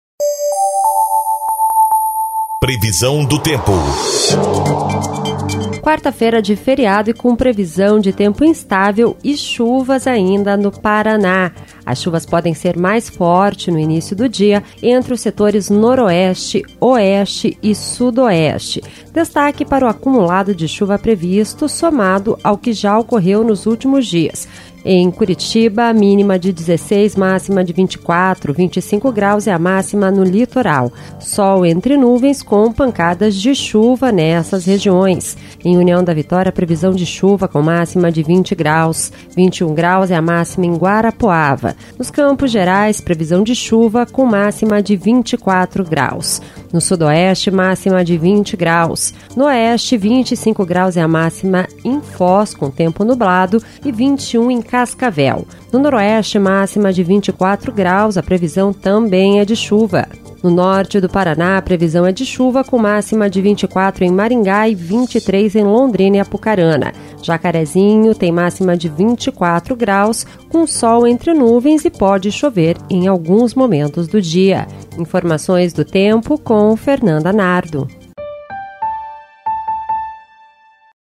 Previsão do Tempo (12/10)